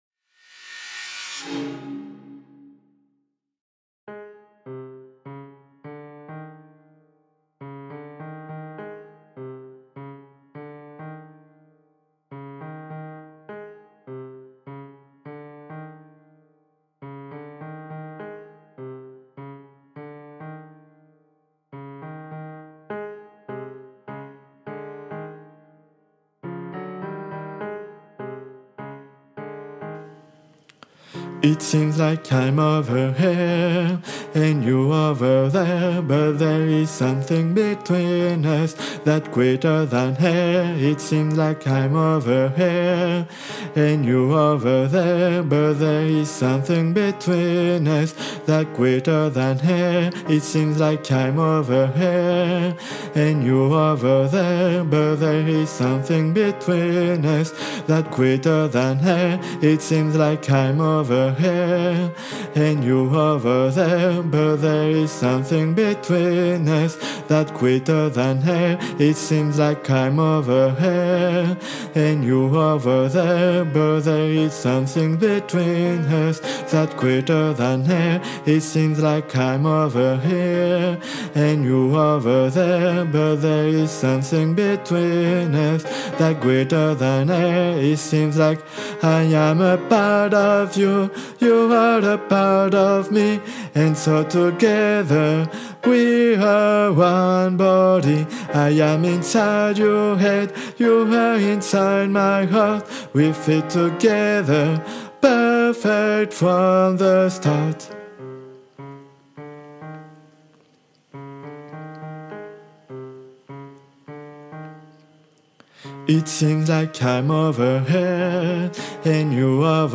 Guide Voix Altos (version chantée)